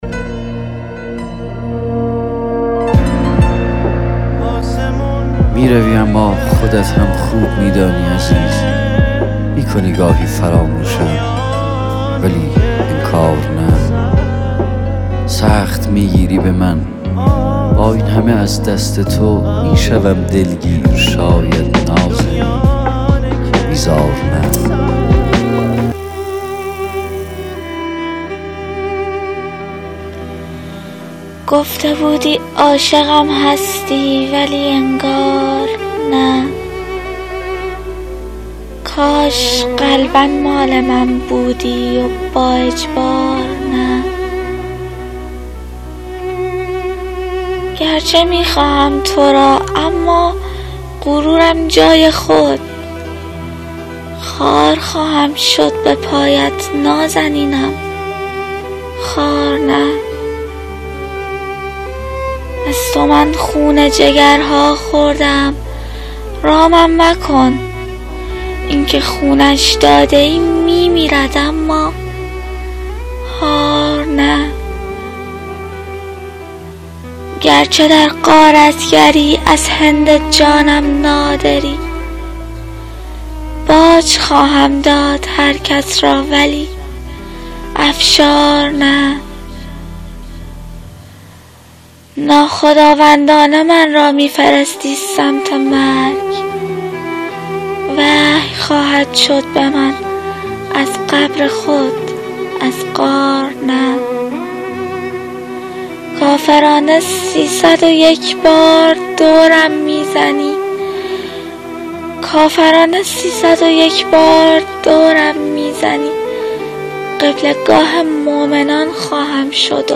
دکلمه شعر غارتگر